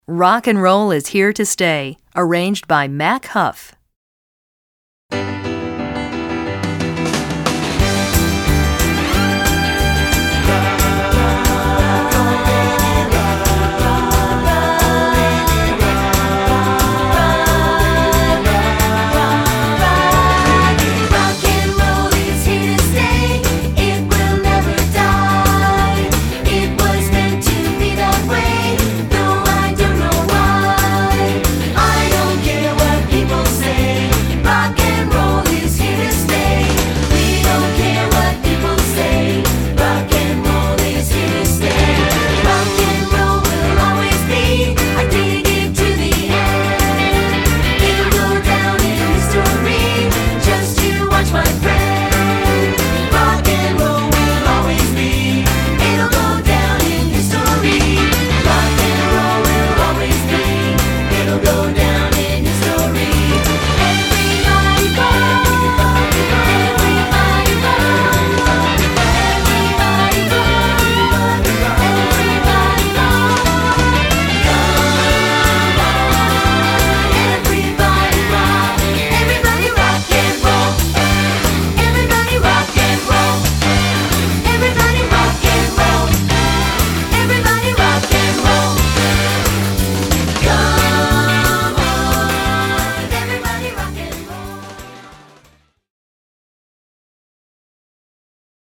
Voicing: VoiceTrax